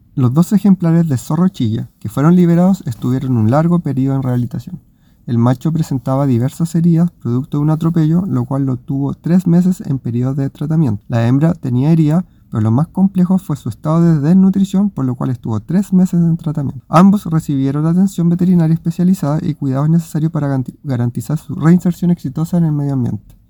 El macho había ingresado con lesiones por atropello, mientras que la hembra llegó desnutrida y con heridas, implicando un proceso de rehabilitación extenso, explicó el director regional del SAG, Francisco Briones.